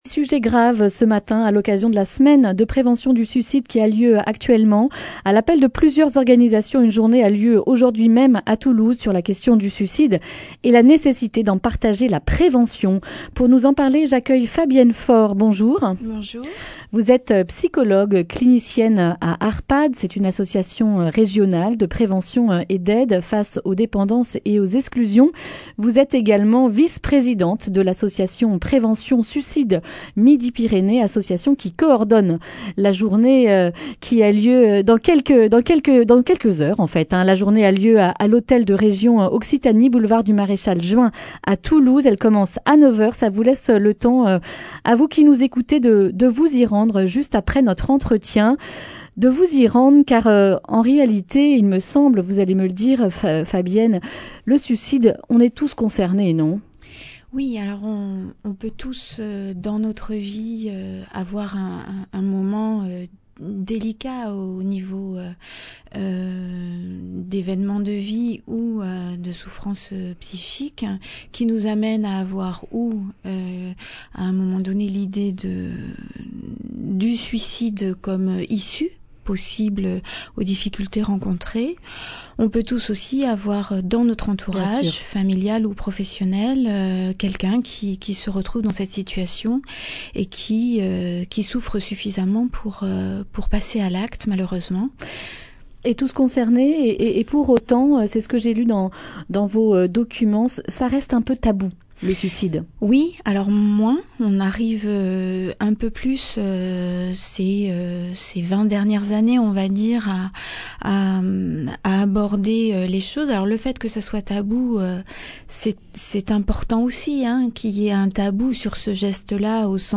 Accueil \ Emissions \ Information \ Régionale \ Le grand entretien \ Journée de prévention du suicide : Tous concernés !